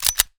sfx / beLoaded